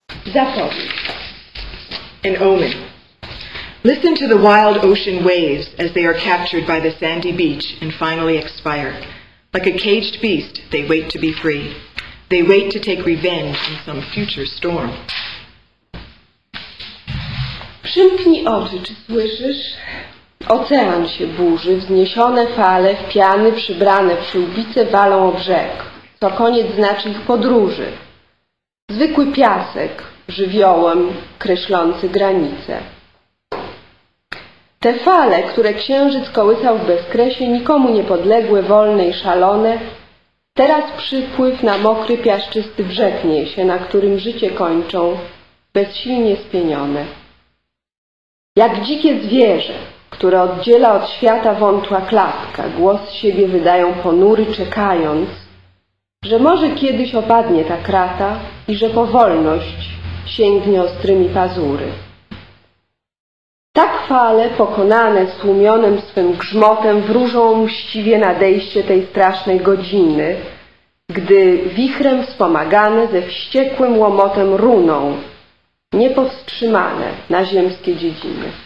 A reading of original poetry
Jones Library Amherst, MA.